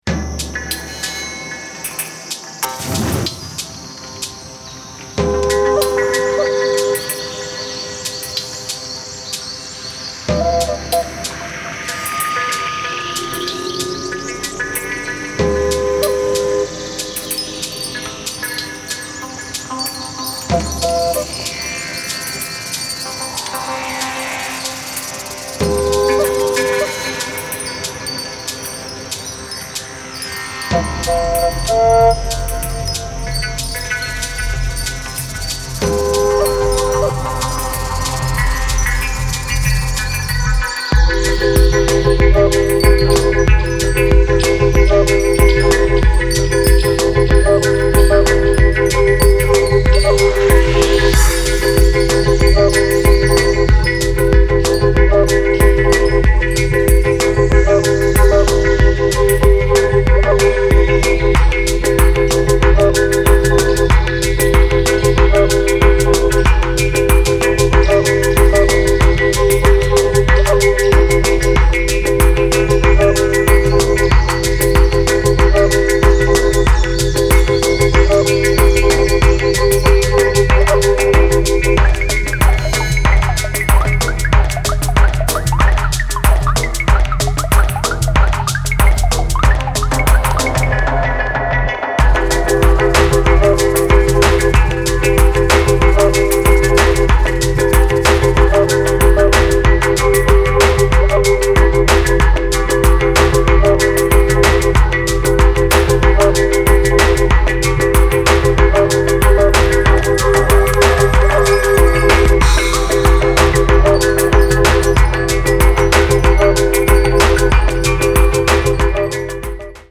Feat. Mixed & Synthesizer
Feat. Percussion
Handpan